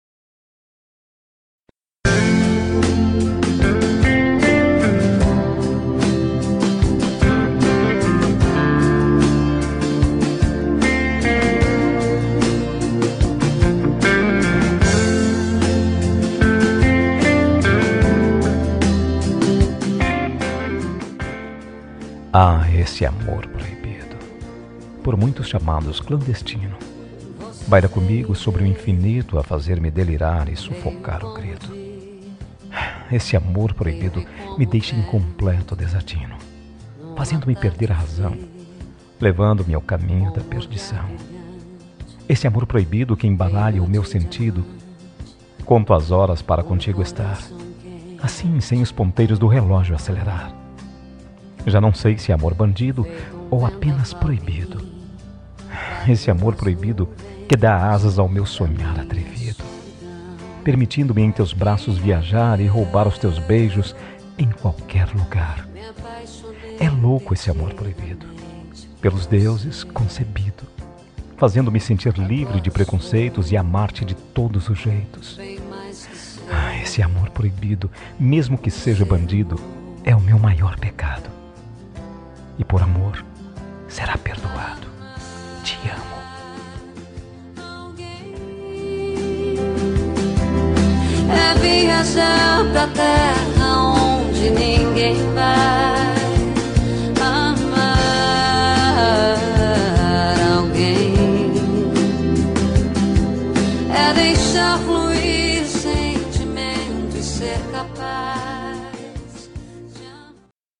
Telemensagem Romântica para Ex. Voz Masculina – Cód: 201678